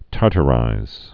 (tärtə-rīz)